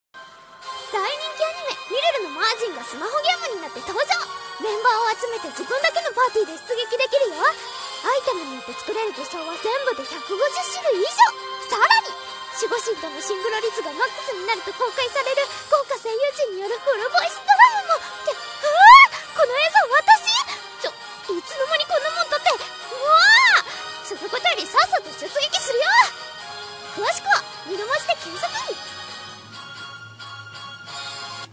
声劇CM